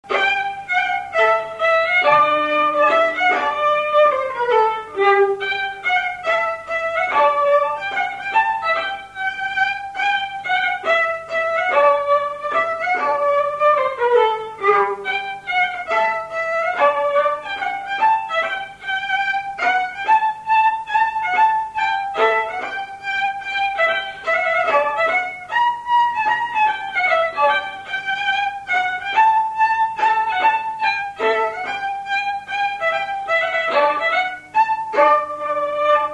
Résumé instrumental
gestuel : danse
Pièce musicale inédite